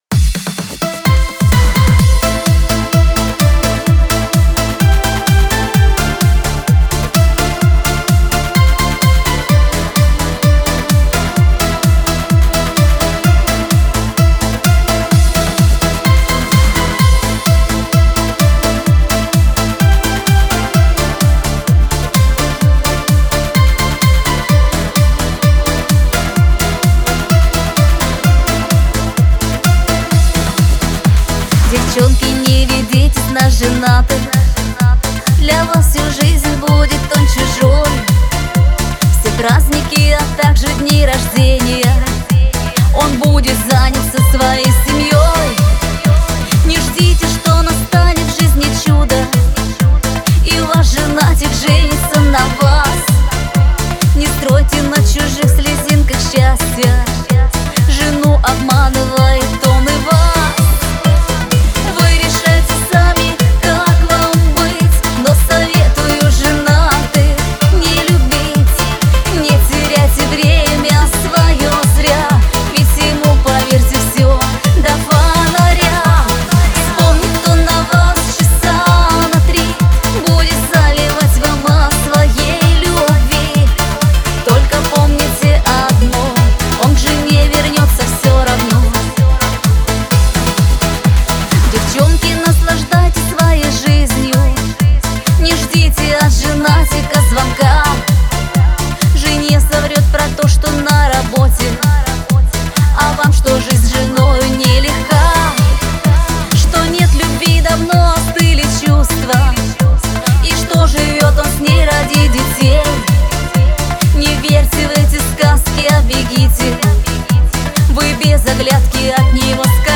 Поп музыка, Русские поп песни